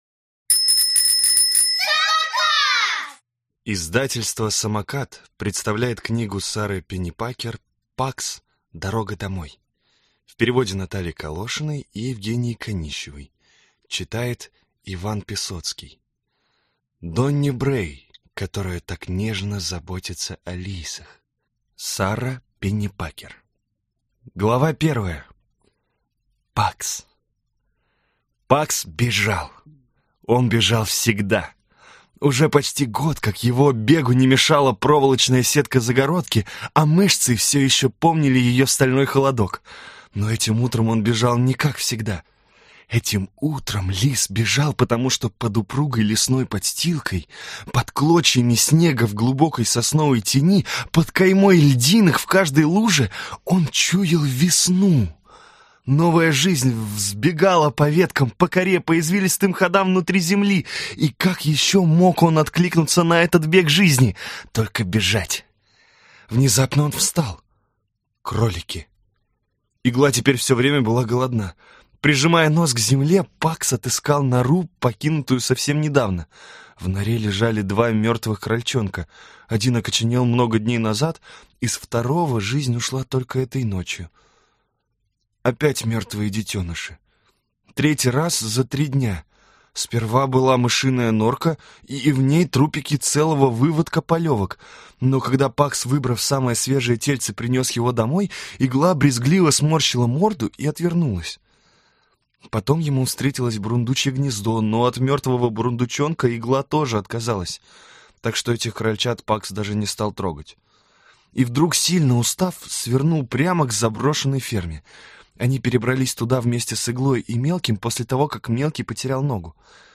Аудиокнига Пакс. Дорога домой | Библиотека аудиокниг